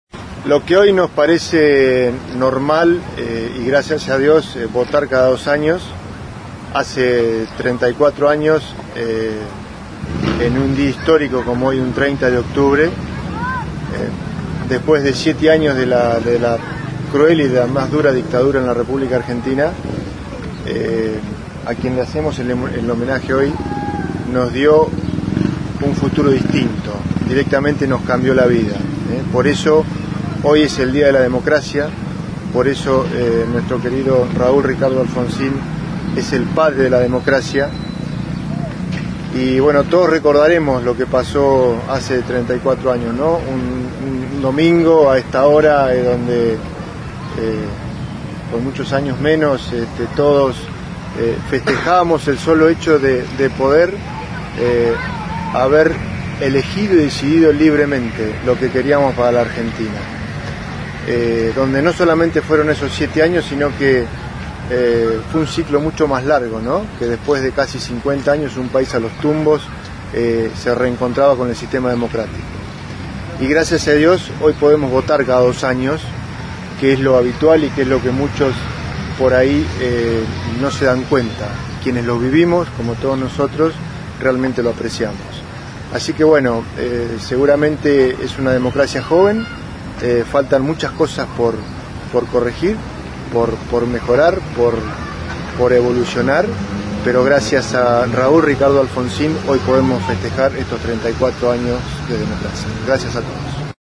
Concejal José Gabriel Erreca